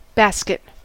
Ääntäminen
Synonyymit basketball hoops shopping cart cart shopping basket car ark lunchbox Ääntäminen : IPA : /ˈbæs.kɪt/ US : IPA : [ˈbæs.kɪt] Tuntematon aksentti: IPA : /ˈbɑːs.kɪt/ IPA : /ˈbaːskət/ Lyhenteet bskt